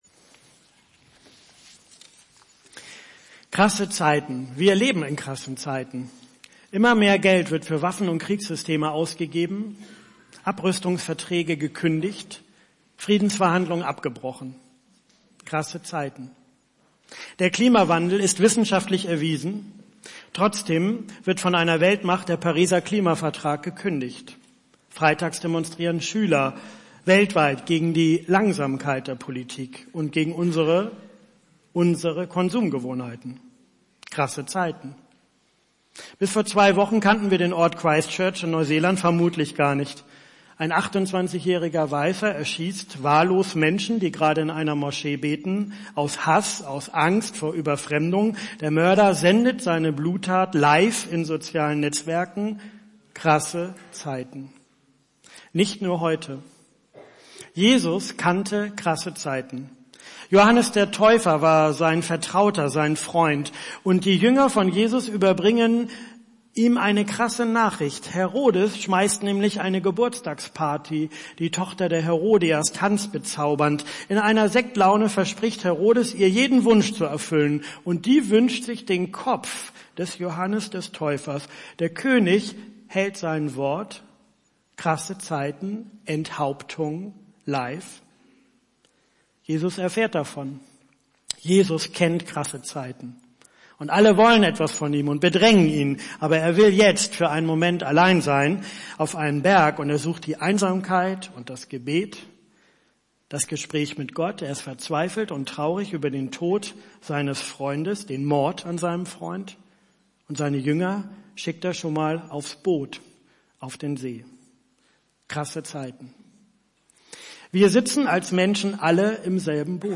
Predigttext: Matthäus 14, 22-33